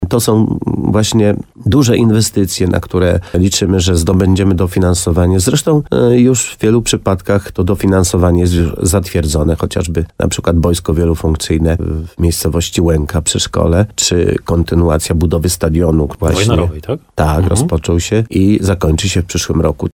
Wszystkiego po trochę i wszystkiego dużo – komentuje wójt gminy Korzenna Leszek Skowron.